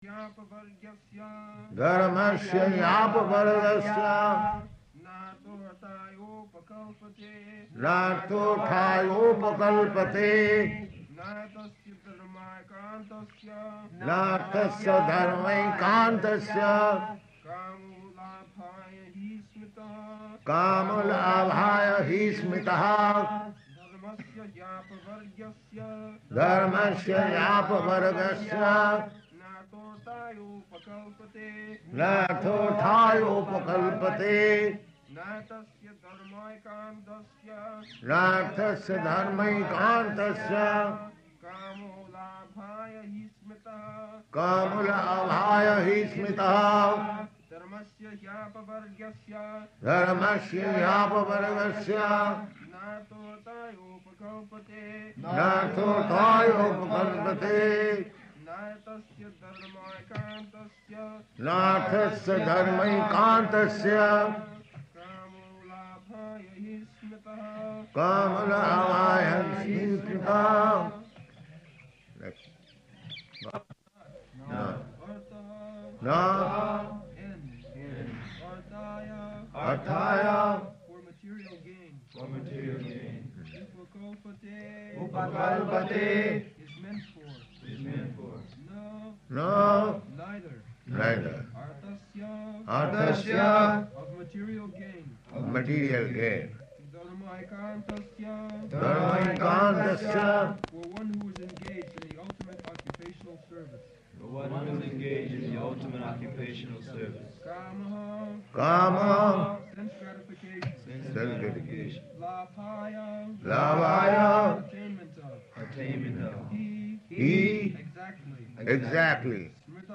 Srila Prabhupada Lectures | There are twenty kinds of dharma śāstra | Srimad Bhagavatam 1-2-8 | Bombay – In Service of Srimad Bhagavatam™ – Podcast